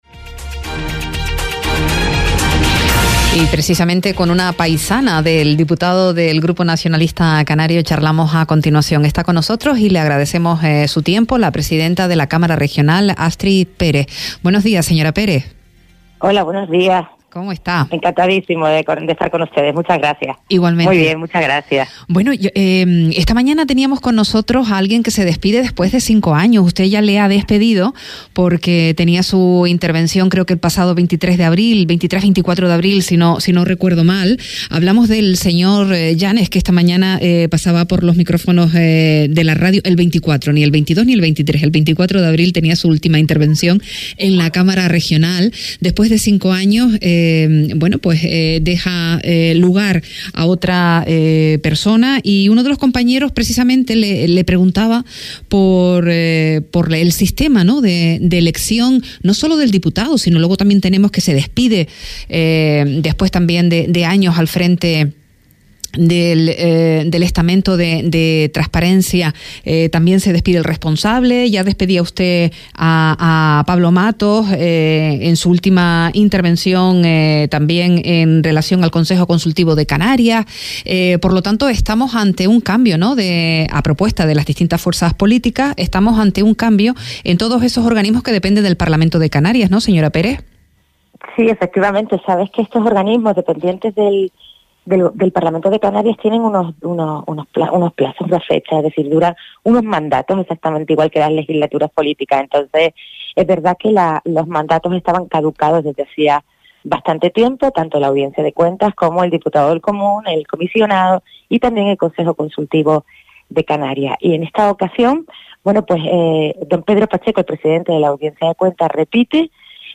Astrid Pérez presidenta de la Cámara regional indicó hoy en Radio Sintonía que desde la presidencia de la institución 'se trabaja en conseguir un Parlamento abierto'.